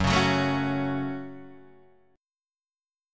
Gbm chord